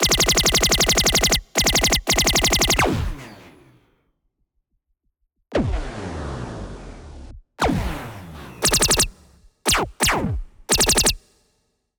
Laser Gun 4
Laser-Gun-04-Example.mp3